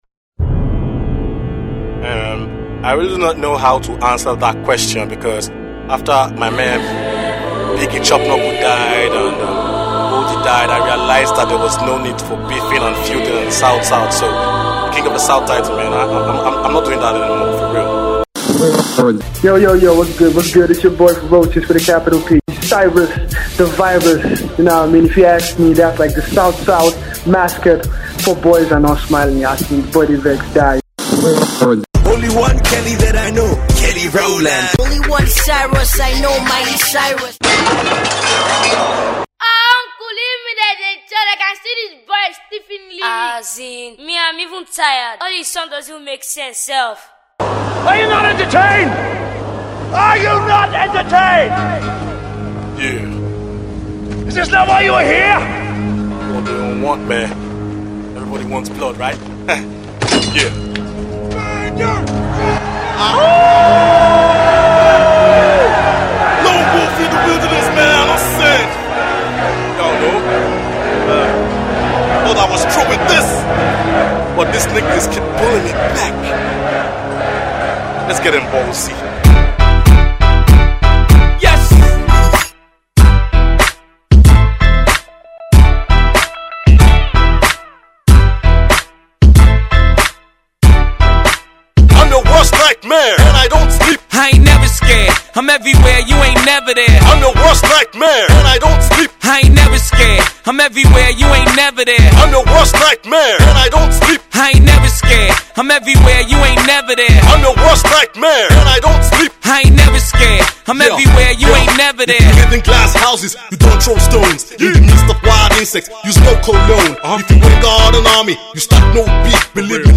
Diss